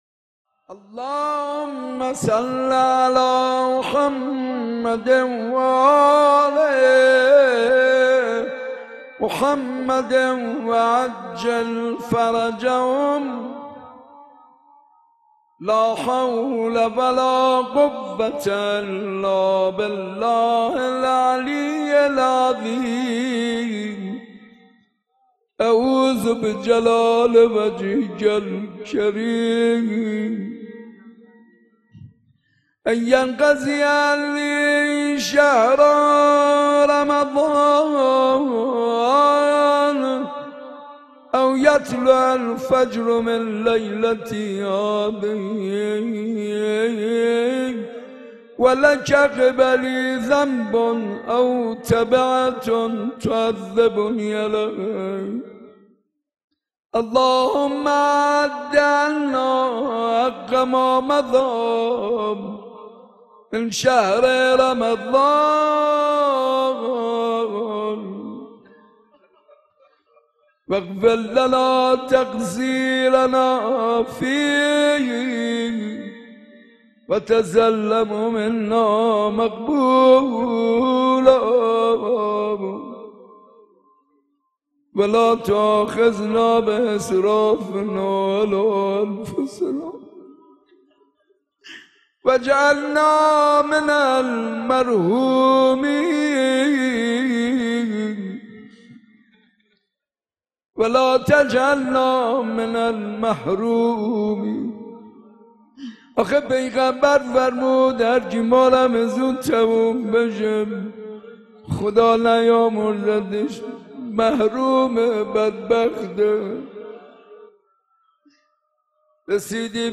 شب بیست و ششم رمضان94 /مسجد ارک